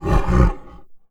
MONSTER_Growl_Subtle_03_mono.wav